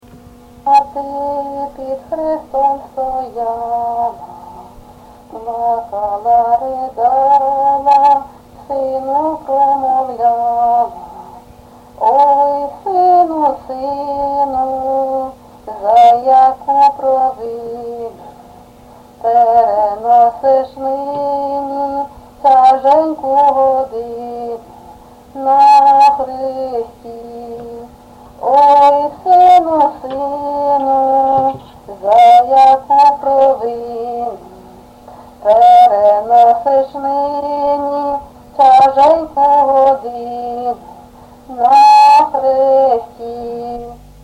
ЖанрПсальми
Місце записум. Сіверськ, Артемівський (Бахмутський) район, Донецька обл., Україна, Слобожанщина